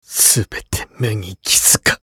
厨二病ボイス～戦闘ボイス～
【敗北ボイス2】